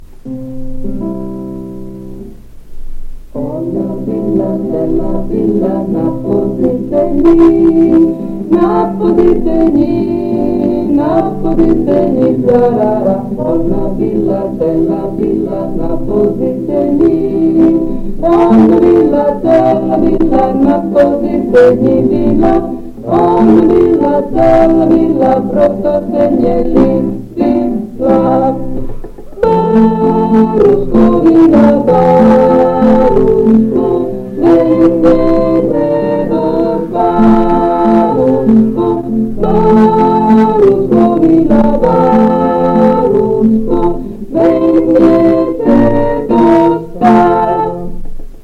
Post 1975. 1 bobina di nastro magnetico.